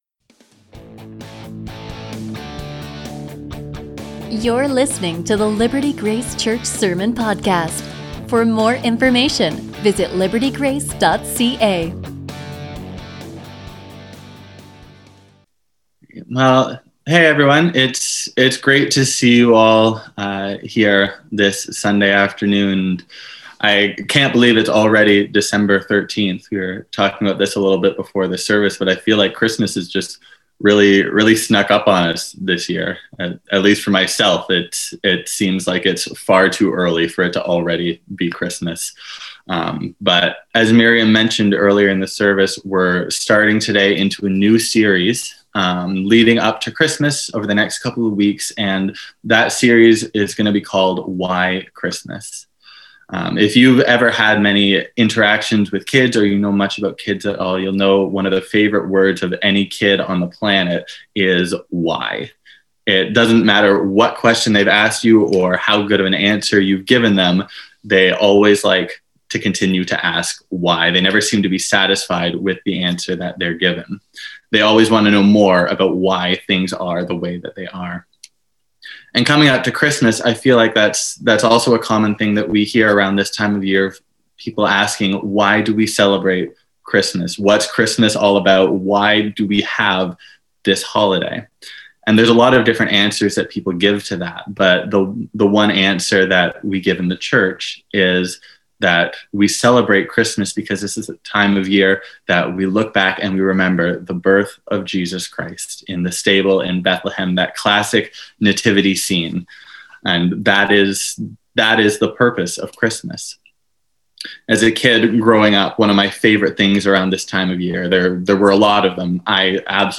A message from the series "Why Christmas?." A sermon from Hebrews 1:1-4